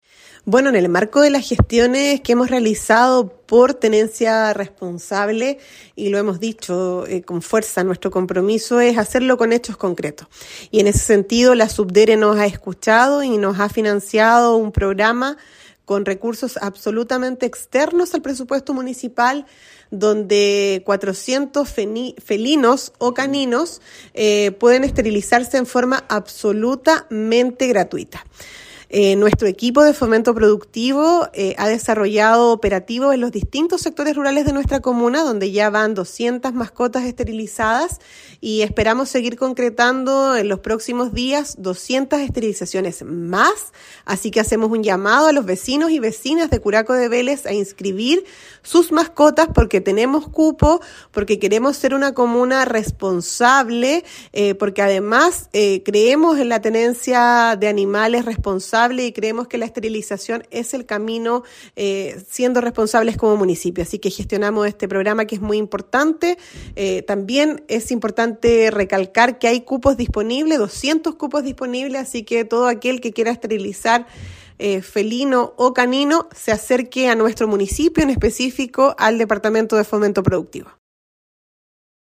Con respecto a esto, la alcaldesa Javiera Yáñez comentó que ya se han desarrollado esterilizaciones e implantaciones de microchip a mascotas en los sectores de Chullec, Curaco urbano, Huyar Alto, Palqui y San Javier, alcanzando 200 caninos y felinos con estos servicios veterinarios sin costo para la comunidad y que buscan fomentar la tenencia responsable de estos animales de compañía:
ALCALDESA-OPERATIVOS-MASCOTAS-.mp3